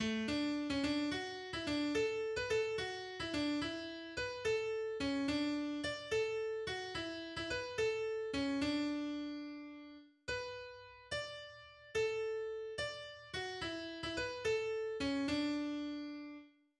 Bergmannslied aus dem 18. Jahrhundert